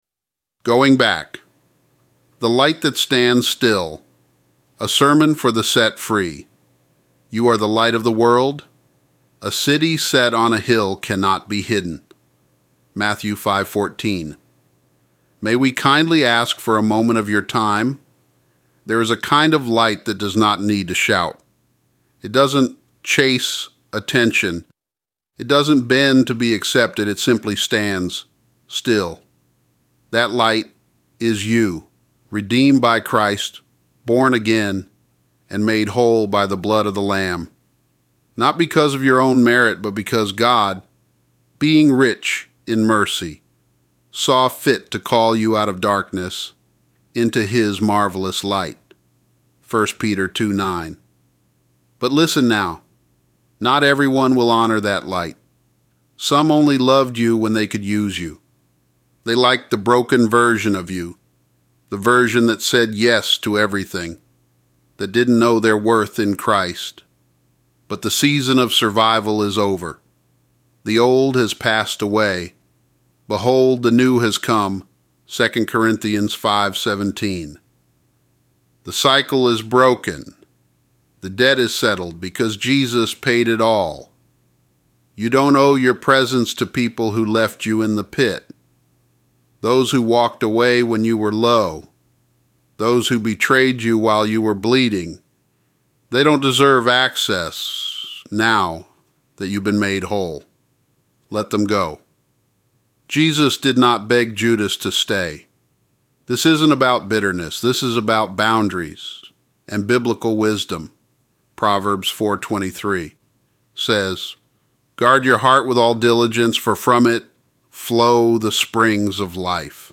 “The Light That Stands Still” — A Sermon for the Set Free